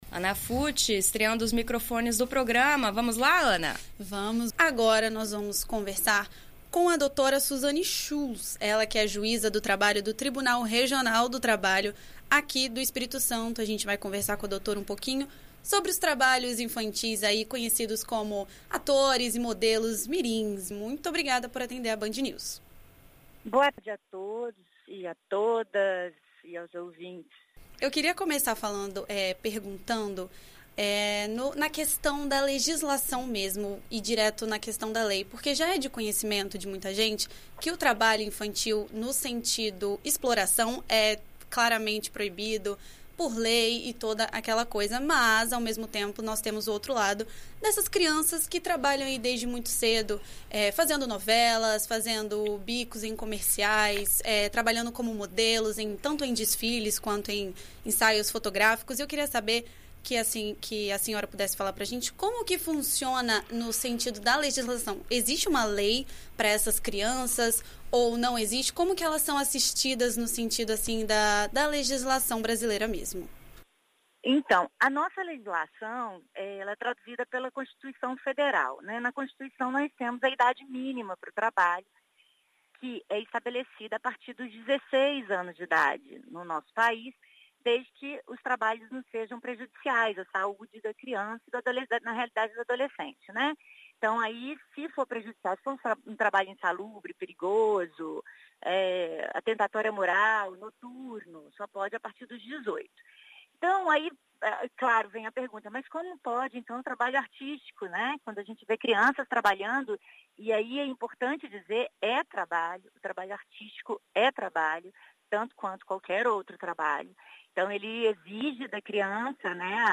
Em entrevista à BandNews FM ES nesta quinta-feira (31), a juíza do trabalho do Tribunal Regional do Trabalho da 17ª Região, Suzane Schulz, explica como funciona casos de trabalho de crianças no mundo artístico.